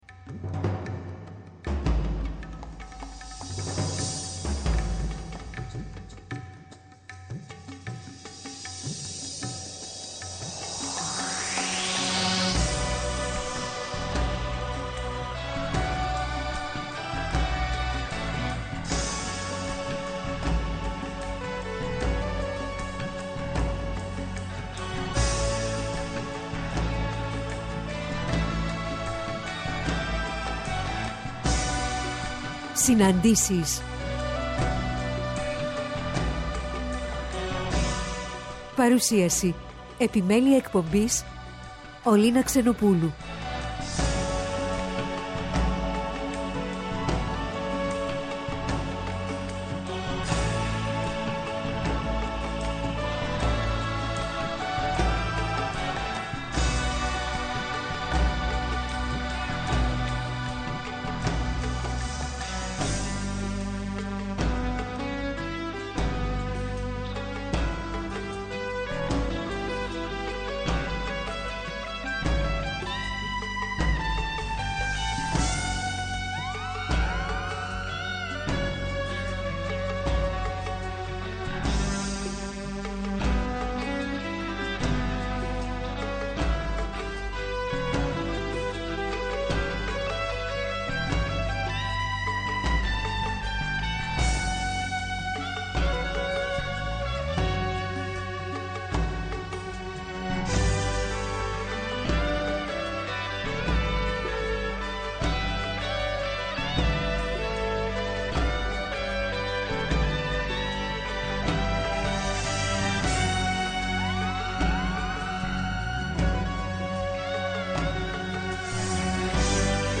Σάββατο 19-11-22 ώρα 16:00-17:00 καλεσμένες τηλεφωνικά: